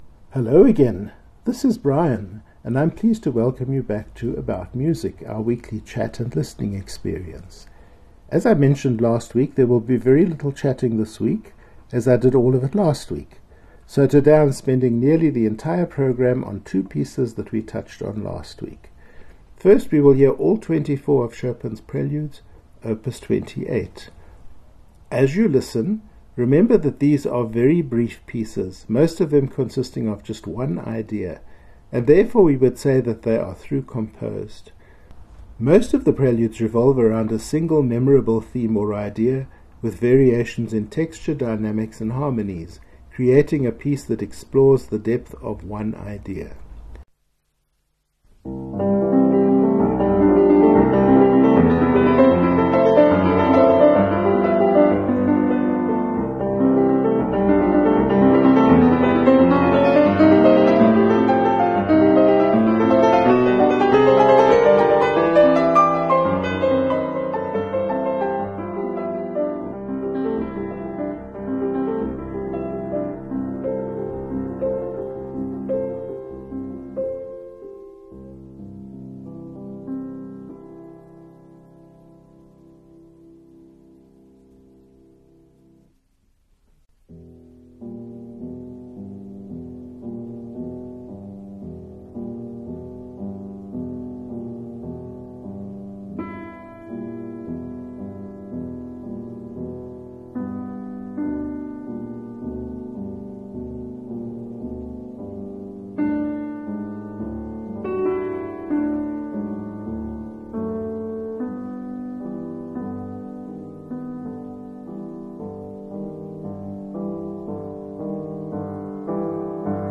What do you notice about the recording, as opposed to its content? As I mentioned last week, there will be very little chatting this week as I did all of it last week.